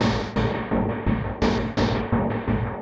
Index of /musicradar/rhythmic-inspiration-samples/85bpm